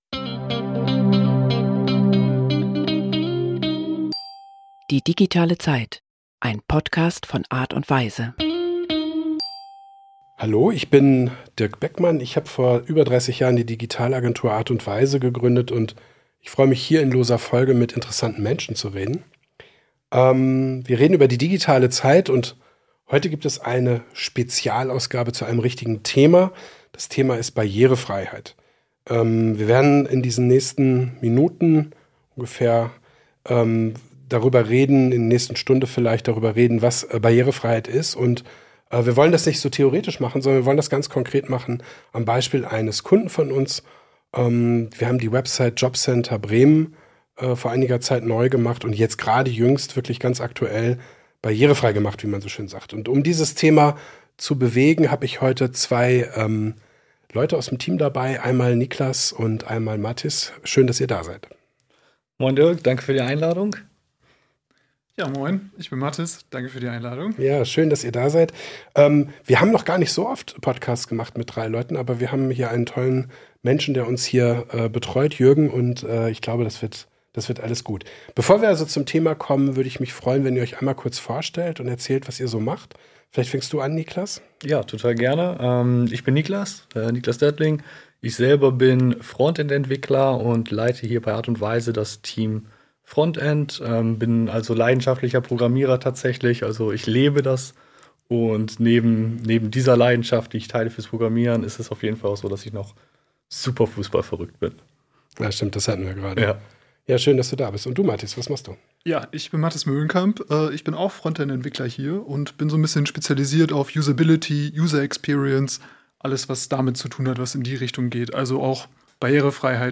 Es geht um Rahmenbedingungen, Technologie und einen amtlichen Test, der zunächst einhundert Fehler aufdeckte. Die beiden Frontend-Entwickler nehmen uns mit auf die Reise und erklären, warum man einfach nur sauber programmieren muss.